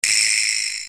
SP VIBRA.wav